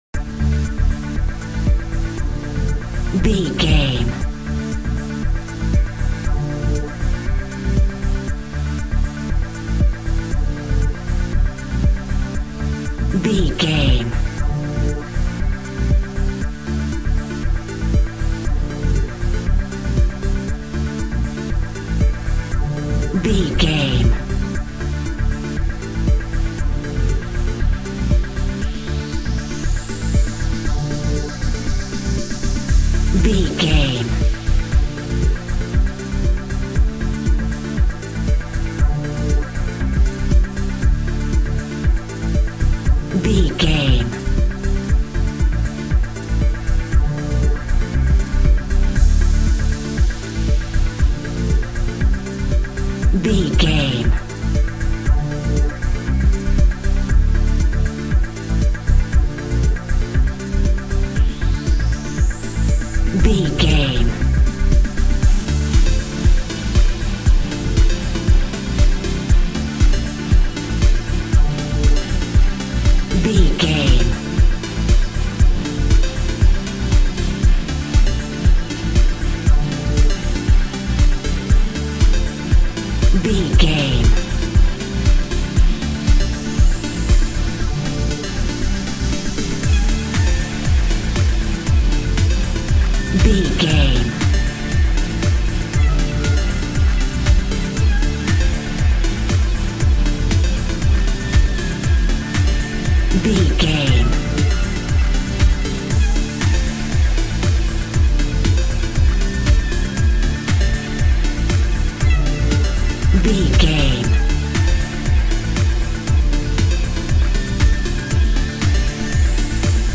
Pop Chart Electronic Dance Music Full.
Fast paced
In-crescendo
Aeolian/Minor
groovy
uplifting
energetic
repetitive
bouncy
synthesiser
drum machine
house
electro dance
synth leads
synth bass
upbeat